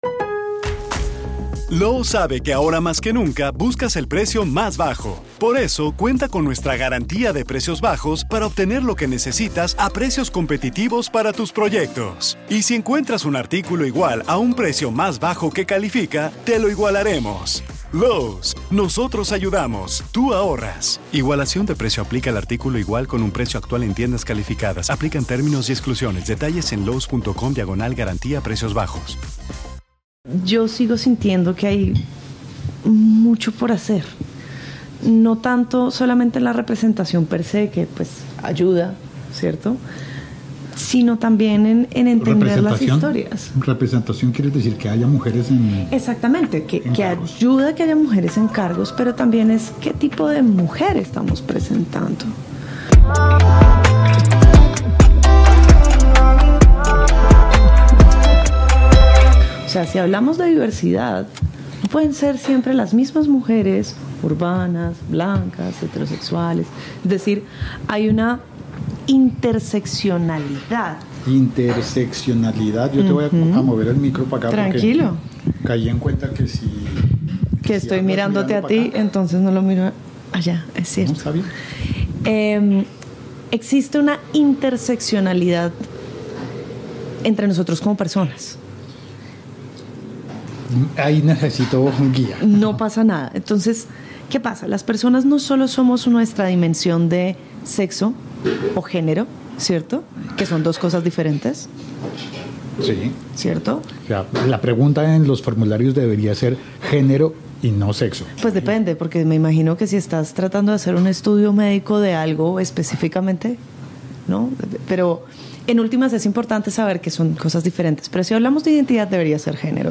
En esta entrevista